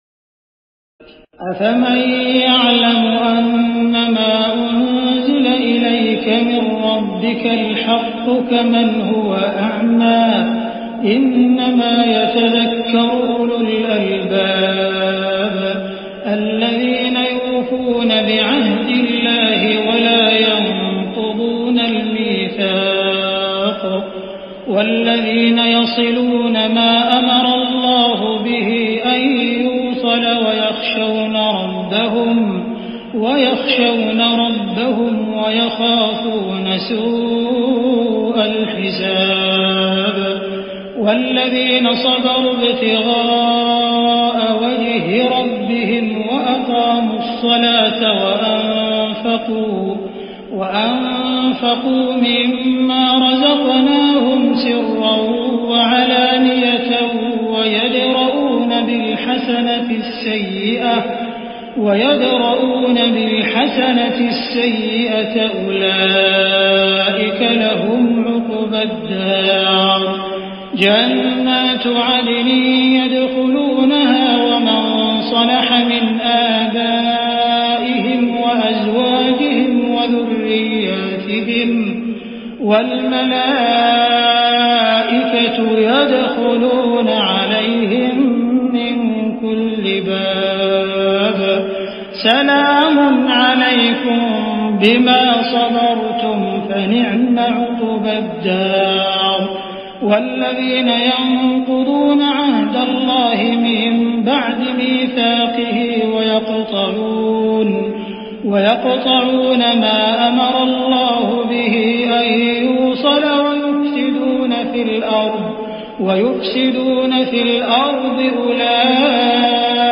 تراويح الليلة الثانية عشر رمضان 1419هـ من سورتي الرعد (19-43) و إبراهيم كاملة Taraweeh 12 st night Ramadan 1419H from Surah Ar-Ra'd and Ibrahim > تراويح الحرم المكي عام 1419 🕋 > التراويح - تلاوات الحرمين